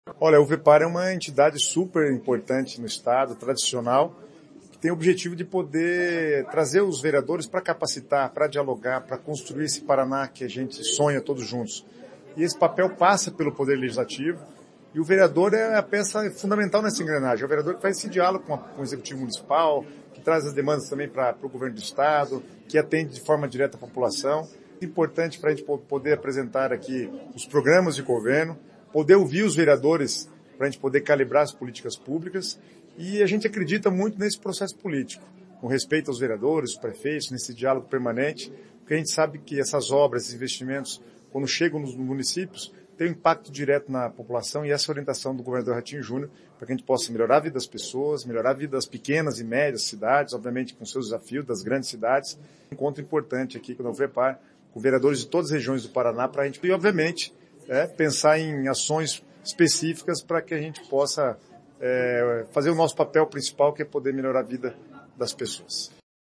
Sonora do secretário de Cidades, Guto Silva, sobre parceria com prefeituras e Legislativos
GUTO SILVA - EVENTO VEREADORES.mp3